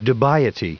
Prononciation du mot dubiety en anglais (fichier audio)
Prononciation du mot : dubiety